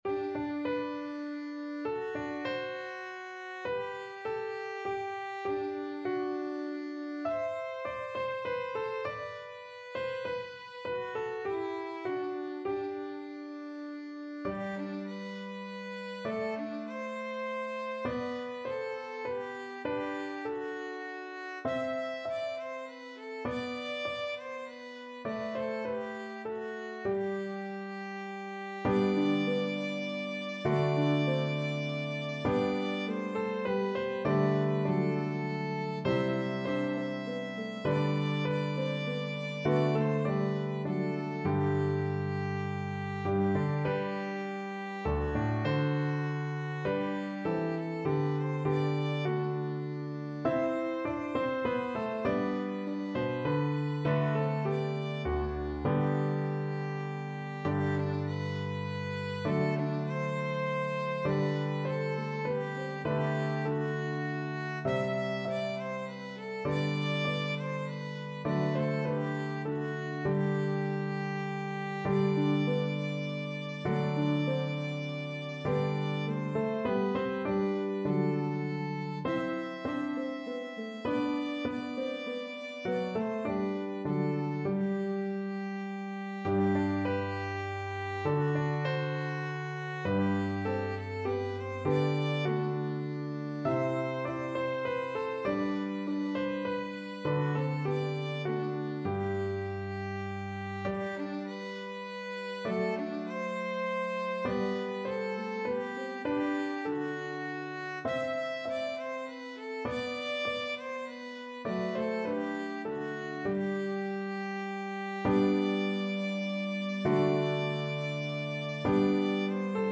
traditional round
Harp, Piano, and Violin version